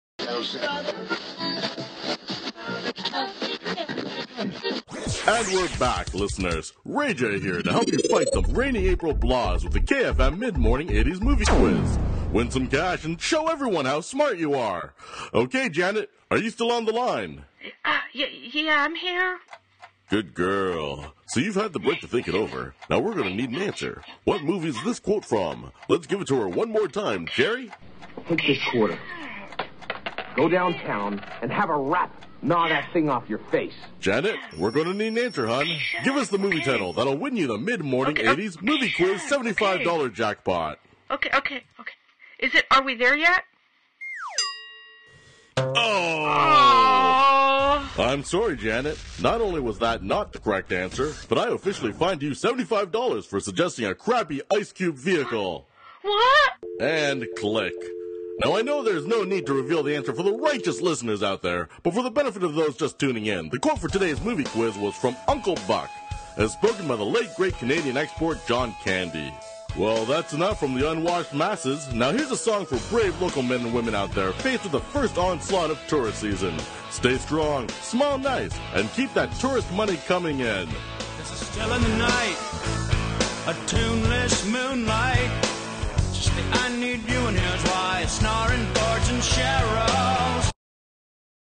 Radio scene from Red Mist